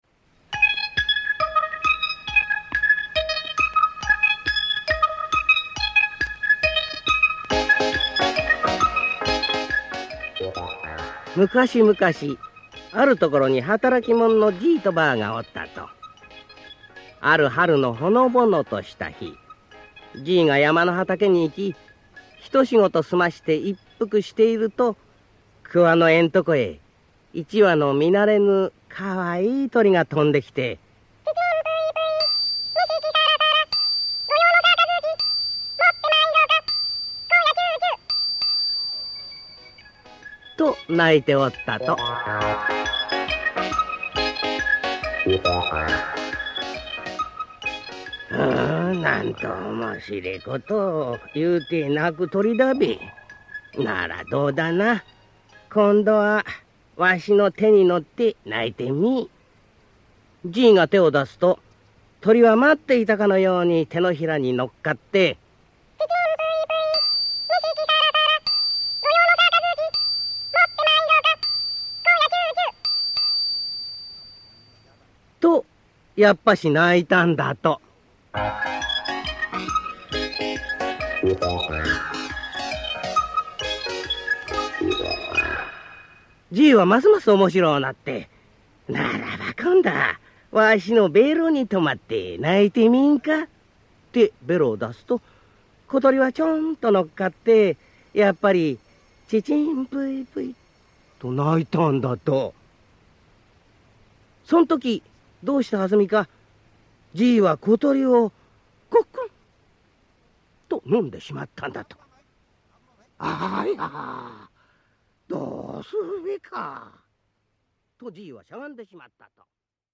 [オーディオブック] 鳥のみじい